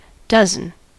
Ääntäminen
IPA : /ˈdʌz.ən/